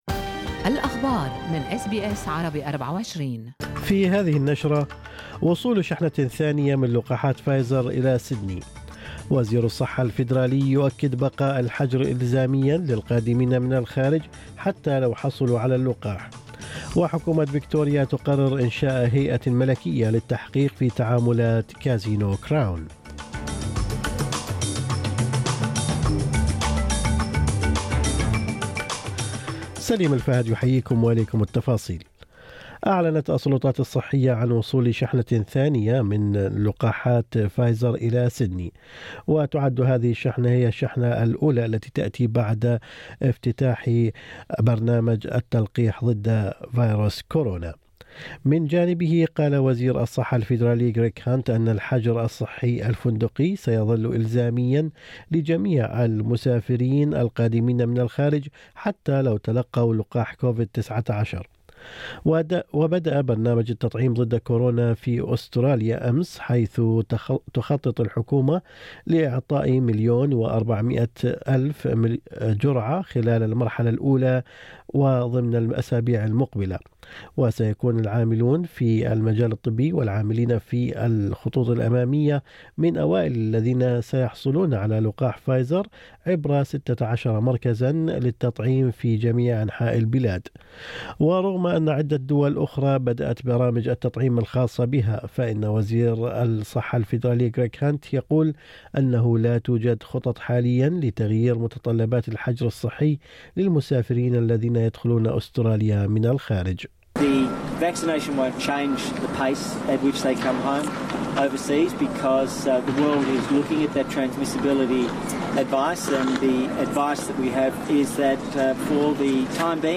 نشرة أخبار الصباح 23/2/2021